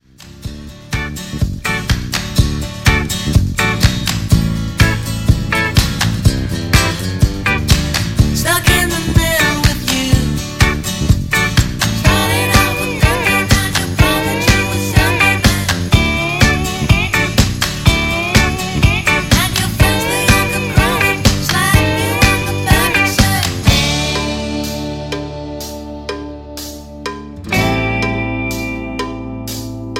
Backing track files: 1970s (954)
Buy With Backing Vocals.